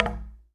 Knock30.wav